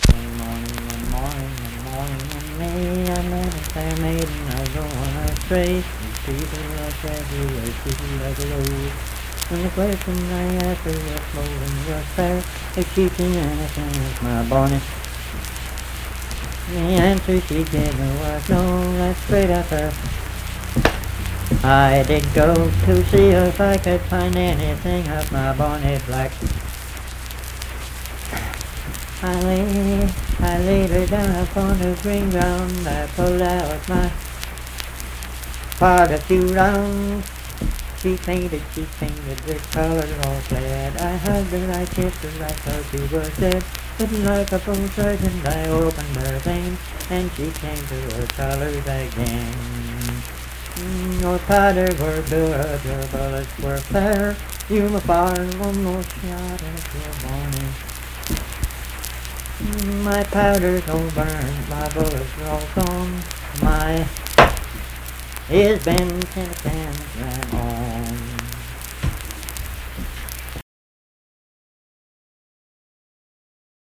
Unaccompanied vocal music
Verse-refrain 5(2-5). Performed in Dundon, Clay County, WV.
Bawdy Songs
Voice (sung)